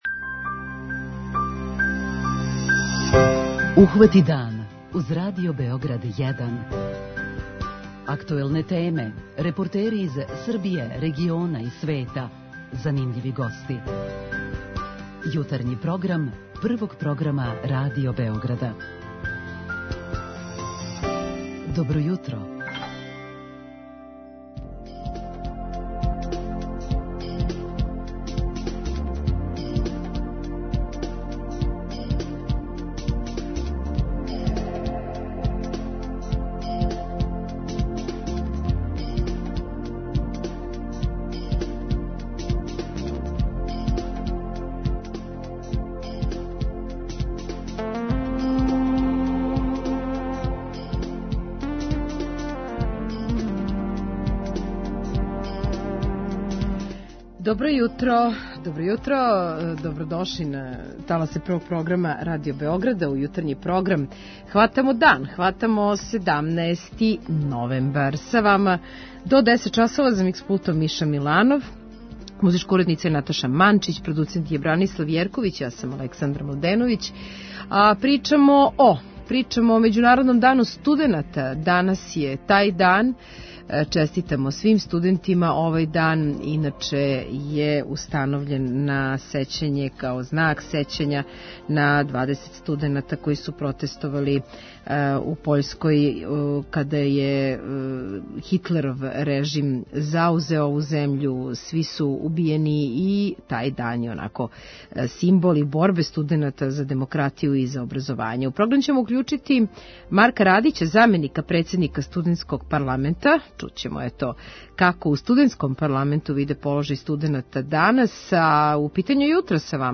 Тај дан је симбол борбе студената за демократију и образовање, а ми га овога јутра обележавамо кроз разговор са представницима Студентског парламента и кроз Питање јутра - Студенти некад и сад.